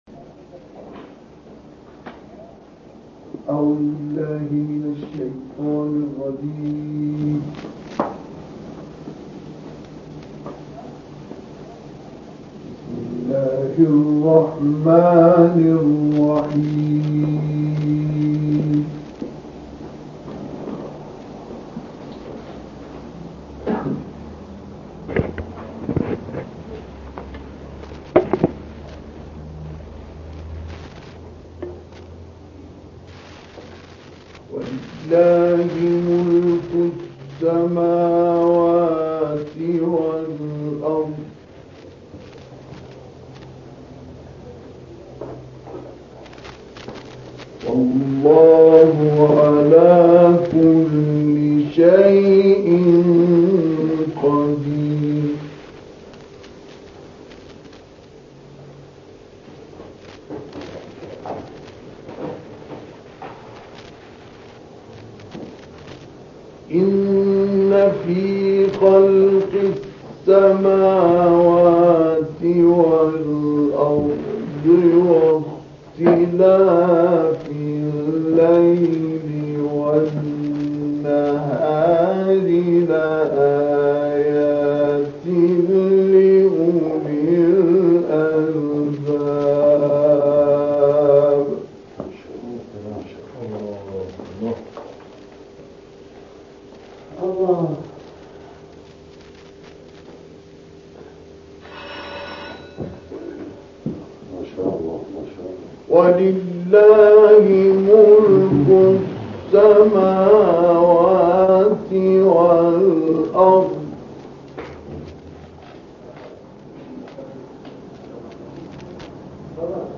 تلاوة رائعة بصوت "الشيخ مصطفى إسماعيل" في العتبة الكاظمية
أصدرت قناة "أكبر القراء" التلغرامية تلاوة رائعة للآية الـ189 لغاية الـ195 لسورة آل عمران، وسورتي "التين"، و"العلق" بصوت القارئ المصري البارز "الشيخ مصطفى إسماعیل"، وذلك لعام 1967 للميلاد في جامع الجوادين بالعتبة الكاظمية.